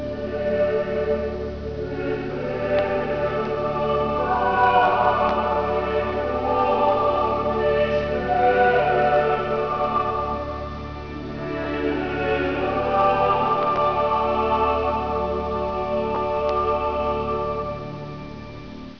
Modern Classical.